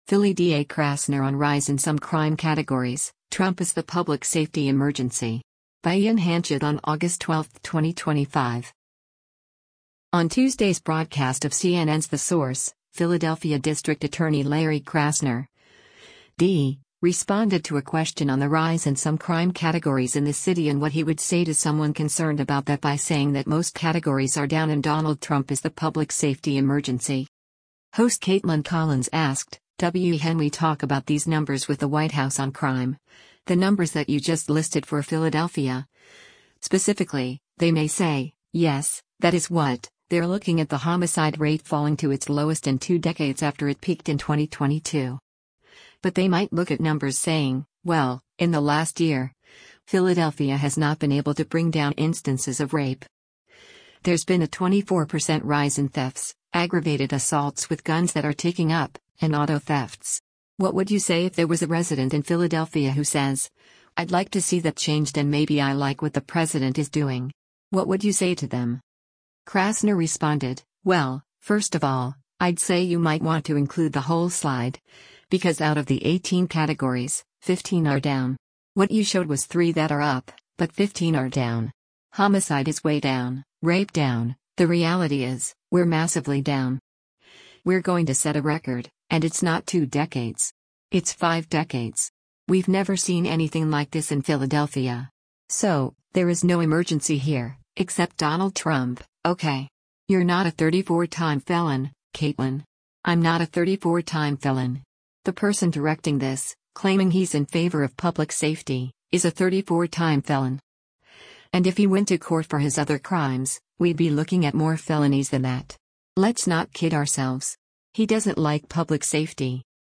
On Tuesday’s broadcast of CNN’s “The Source,” Philadelphia District Attorney Larry Krasner (D) responded to a question on the rise in some crime categories in the city and what he would say to someone concerned about that by saying that most categories are down and “Donald Trump is the public safety emergency.”